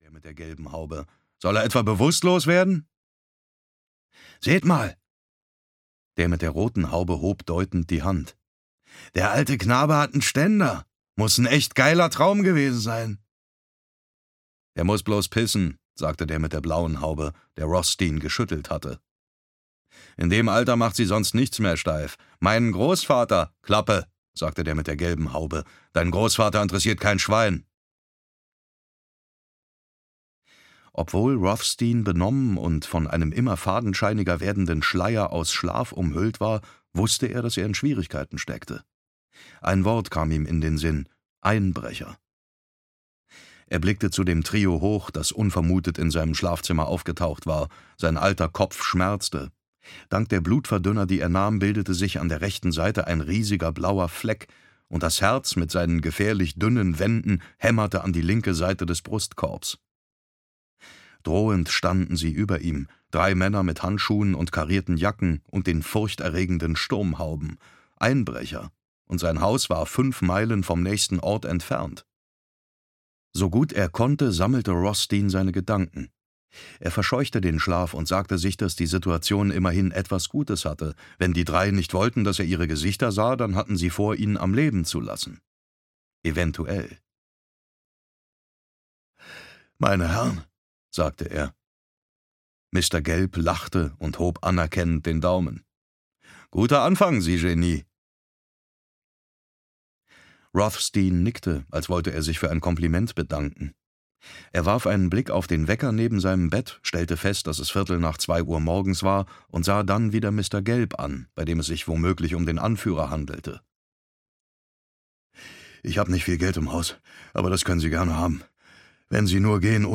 Finderlohn (DE) audiokniha
Ukázka z knihy
• InterpretDavid Nathan